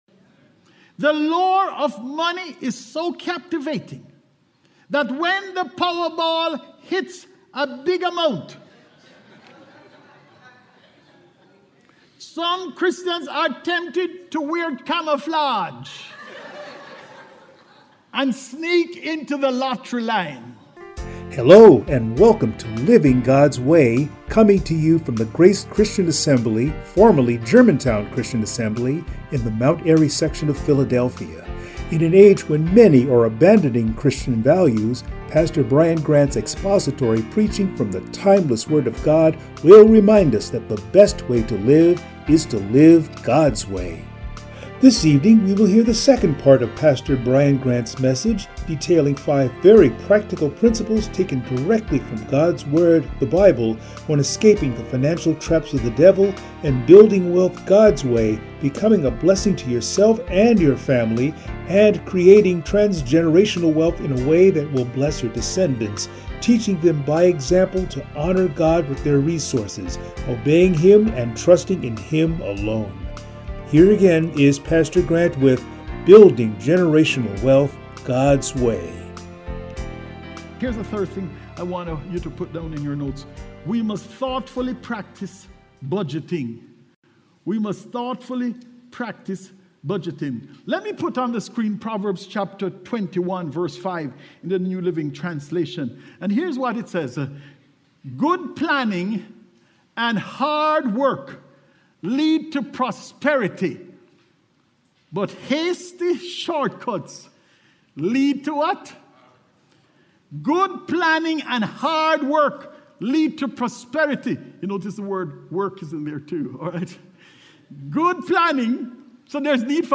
Passage: Deuteronomy 8:11-20 Service Type: Sunday Morning